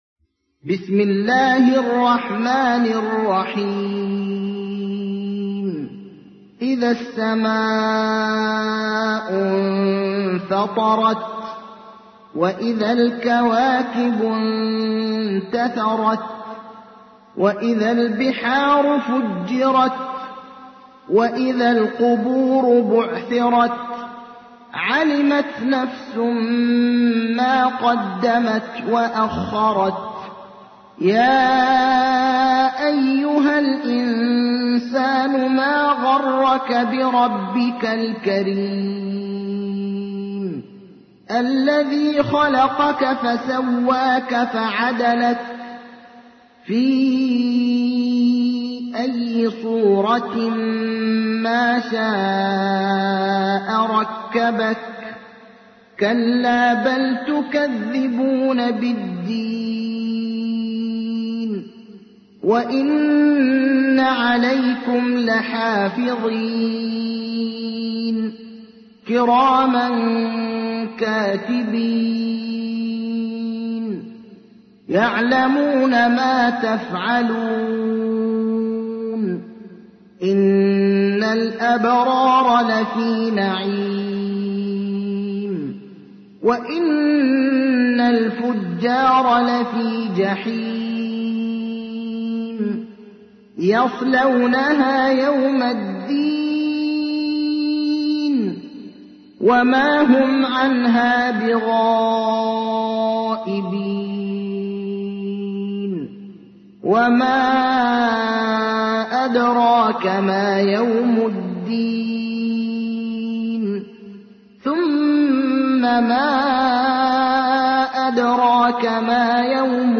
تحميل : 82. سورة الانفطار / القارئ ابراهيم الأخضر / القرآن الكريم / موقع يا حسين